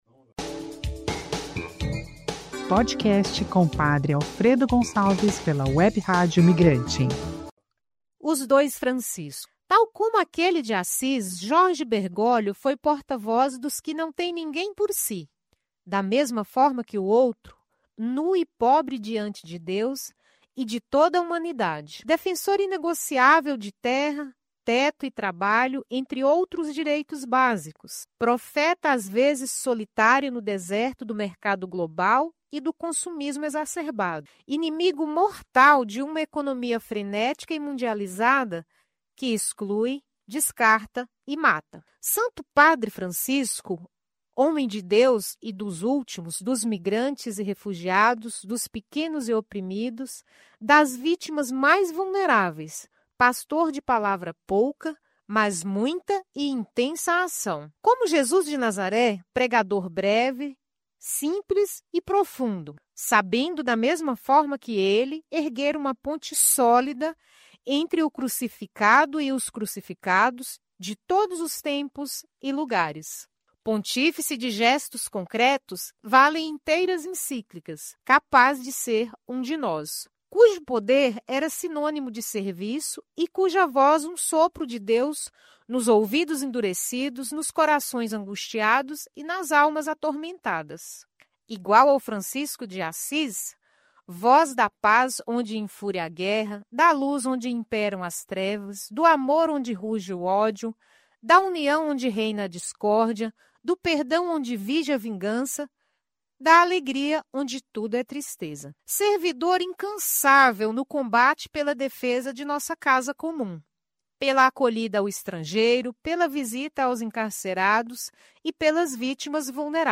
Gravado e editado nos Estudios da web Radio Migrante Arte